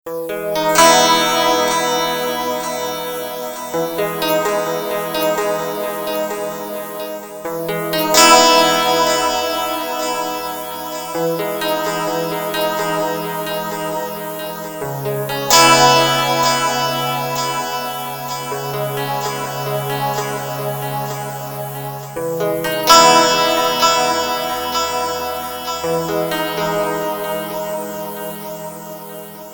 loopstation " 吉他循环
描述：朦胧的吉他循环
标签： 循环 FX 通风 吉他
声道立体声